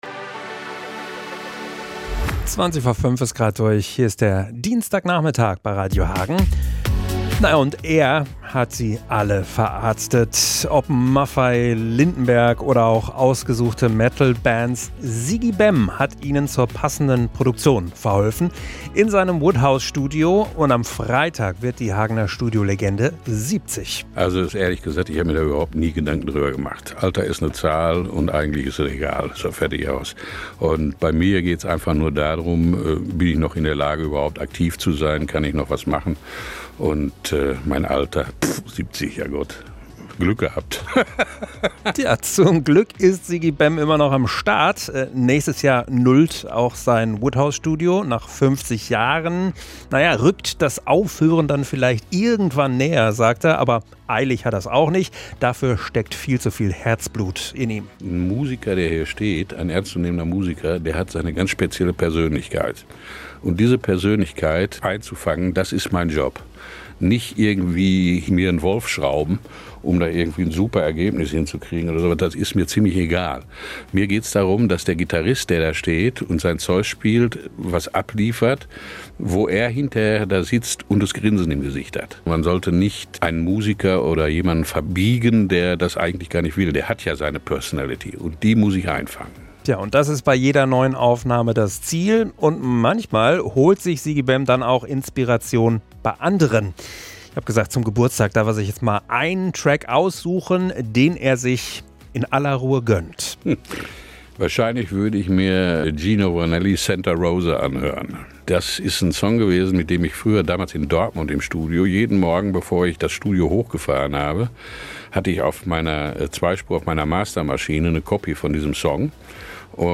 MITSCHNITT AUS DER SENDUNG I